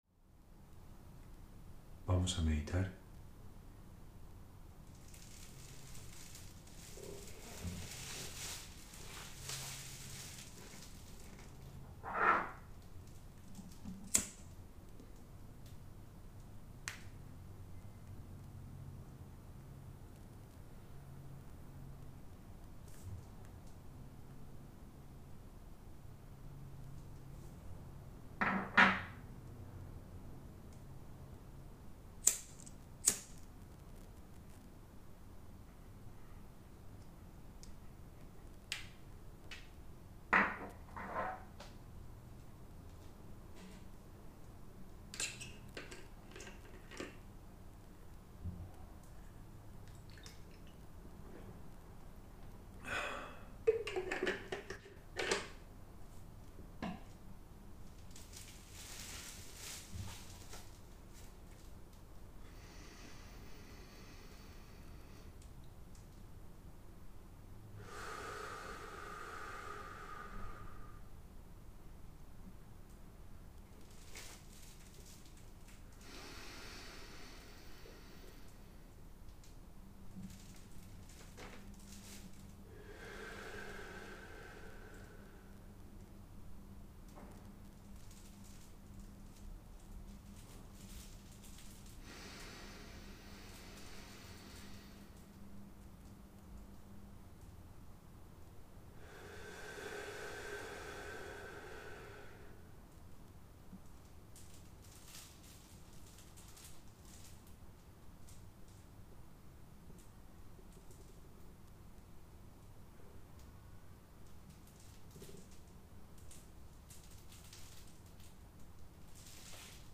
Meditación en tiempo real para conectar en la misma frecuencia Hosted on Acast.